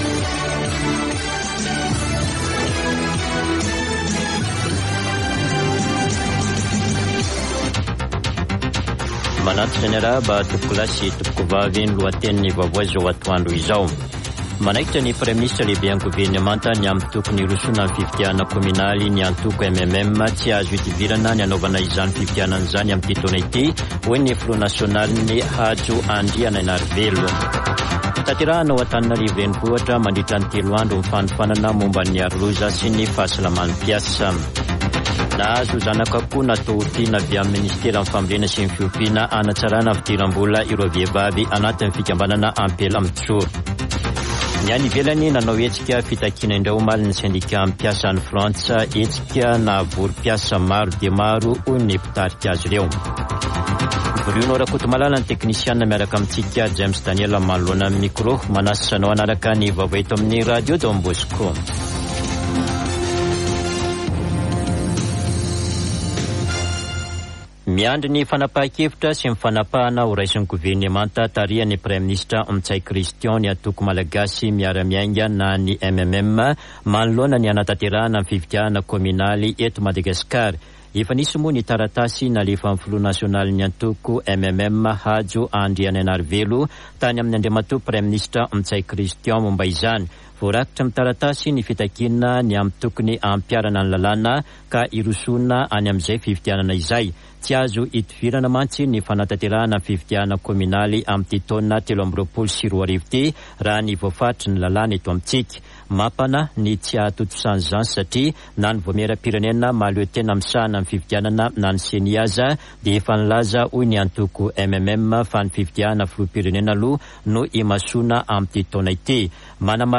[Vaovao antoandro] Alarobia 08 marsa 2023